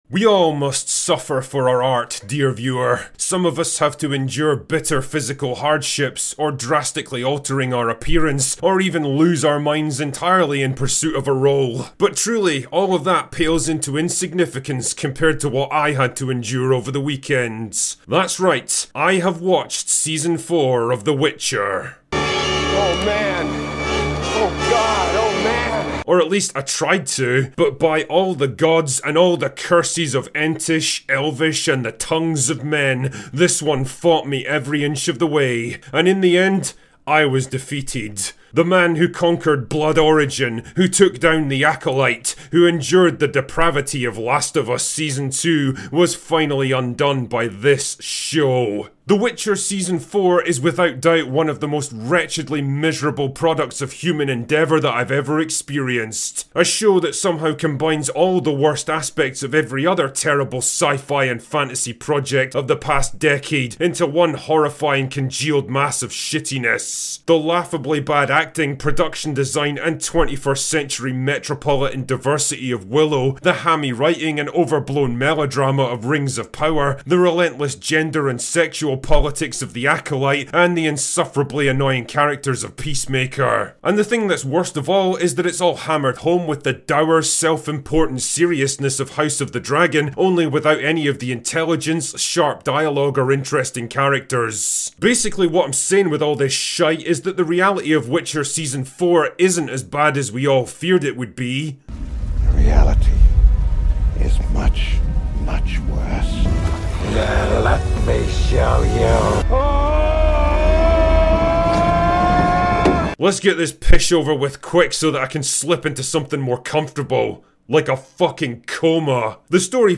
I did the best I could, but The Witcher Season 4 defeated me, and forced me to give up. This is my sort-of-review/rant at the absolute calamity from Netflix.